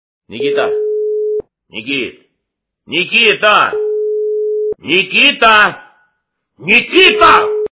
» Звуки » Именные звонки » Именной звонок для Никиты - Никита, Никит, Никита, Никита, Никита